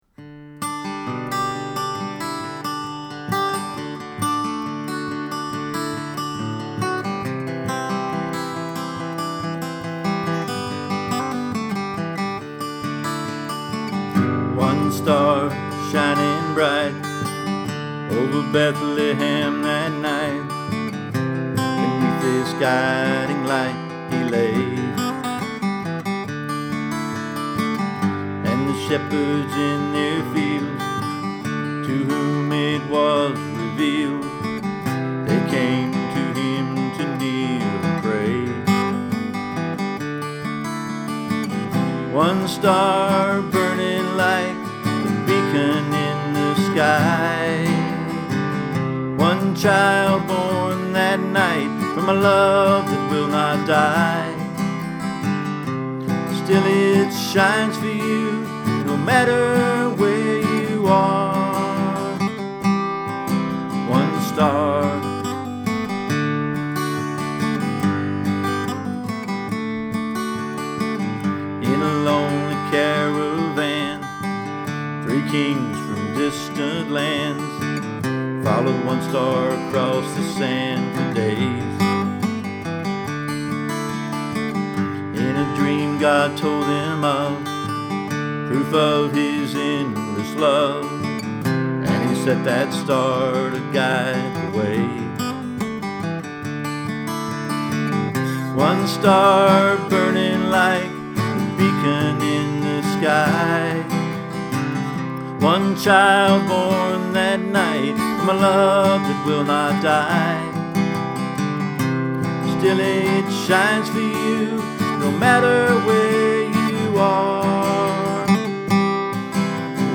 Here are 3 Christmas songs I have written over the years. These recordings are admittedly lo-fidelity. I made them in Garage Band sitting in my living room with just my guitar and a microphone connected to my laptop.